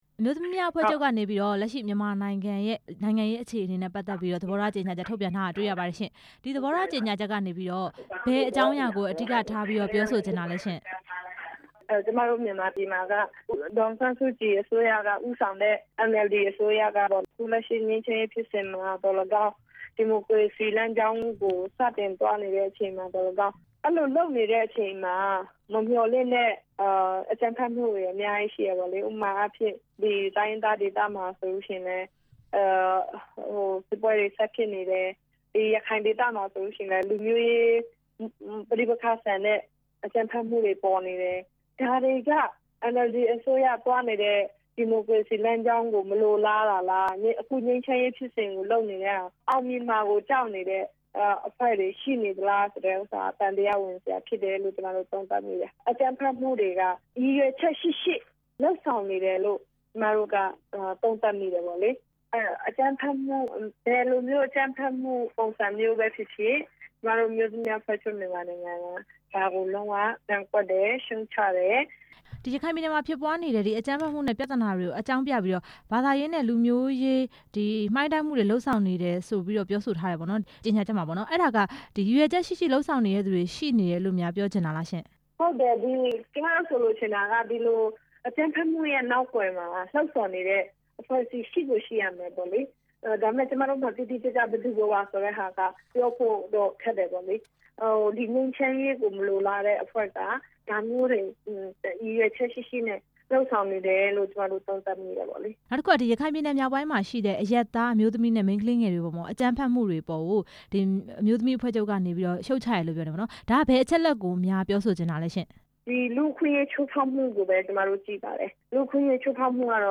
အမျိုးသမီးများအဖွဲ့ချုပ် (မြန်မာနိုင်ငံ)ရဲ့ ကြေညာချက်အကြောင်း မေးမြန်းချက်